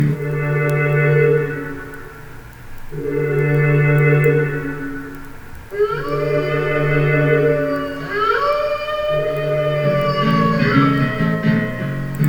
wolves.819e965a.mp3